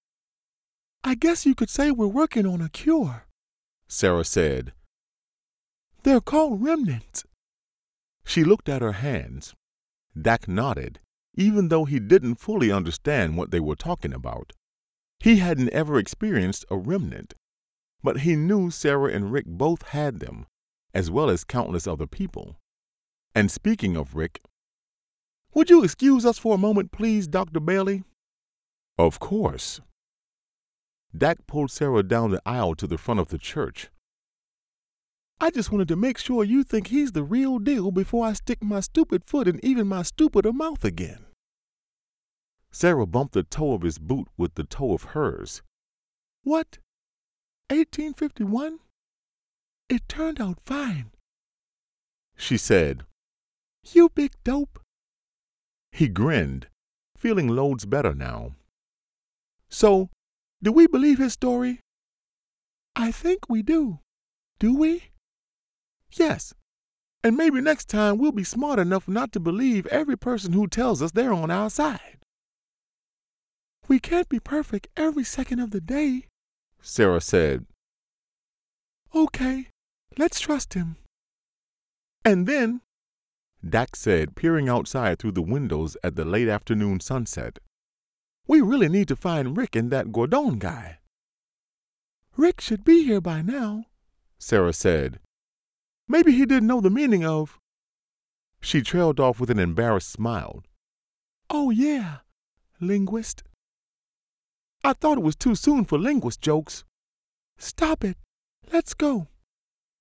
Male
Adult (30-50), Older Sound (50+)
Audiobooks